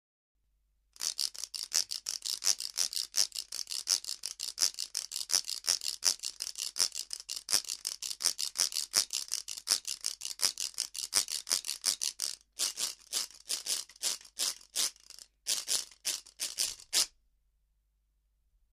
びんざさらモドキ
持ち手が紐になっています　板が布ベルトについているので　動きがしなやかです